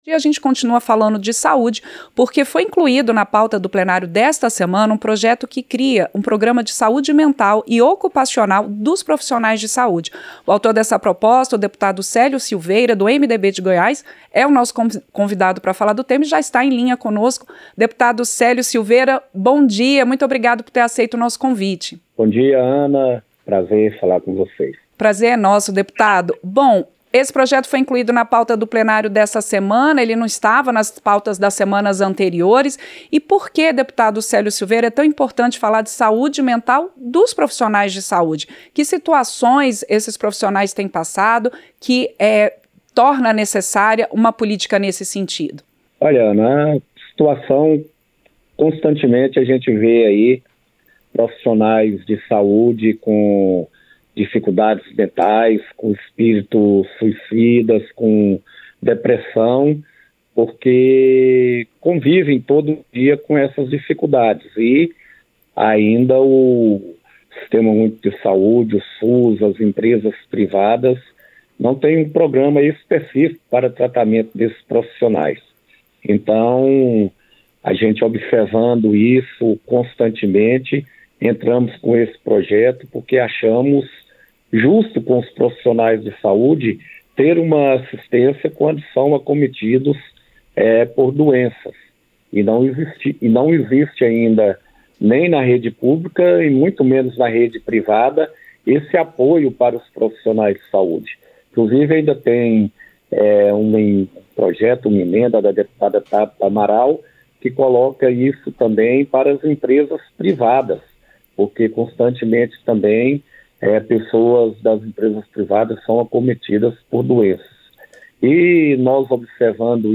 • Entrevista - Dep. Célio Silveira (MDB-GO)
Programa ao vivo com reportagens, entrevistas sobre temas relacionados à Câmara dos Deputados, e o que vai ser destaque durante a semana.